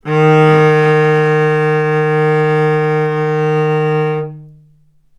vc-D#3-ff.AIF